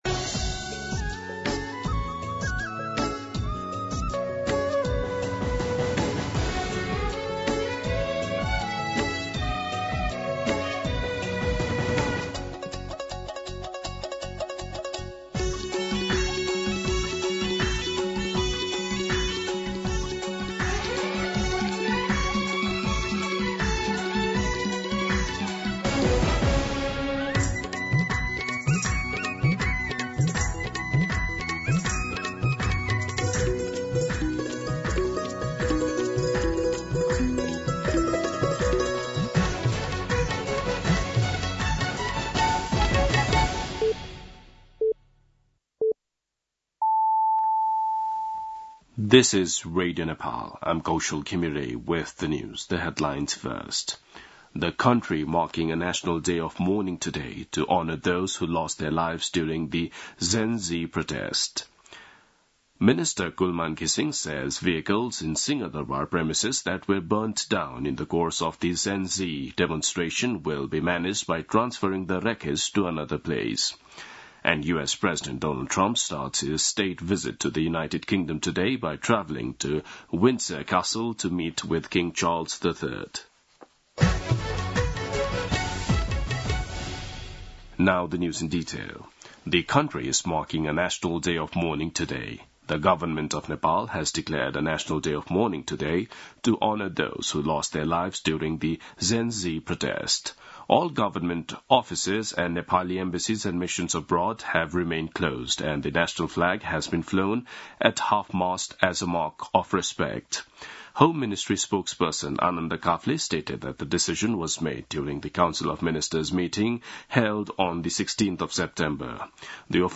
दिउँसो २ बजेको अङ्ग्रेजी समाचार : १ असोज , २०८२
2-pm-English-News-3.mp3